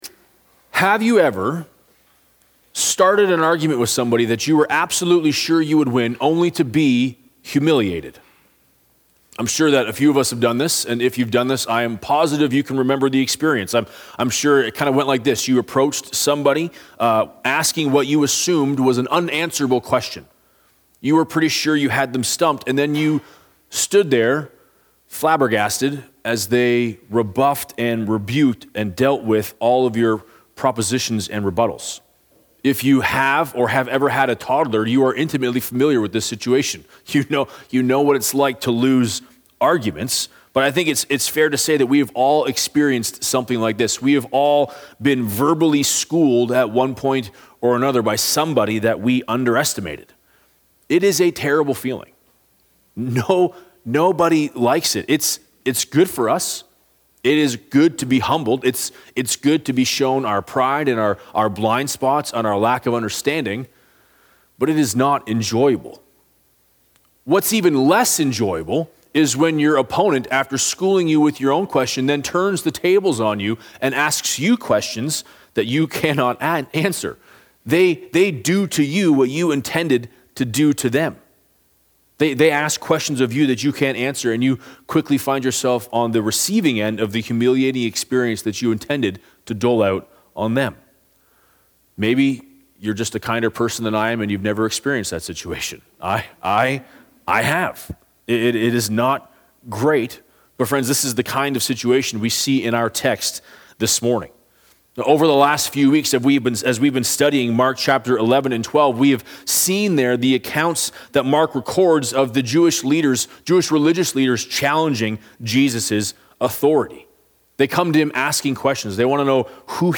Continuation of a sermon series on Mark.
Download JSAC Sermons Sunday morning sermons from Jacqueline Street Alliance Church.